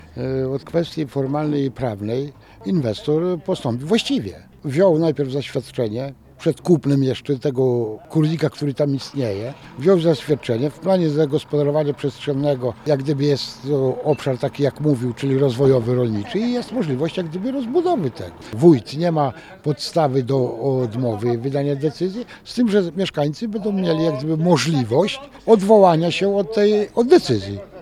rolnik-3.mp3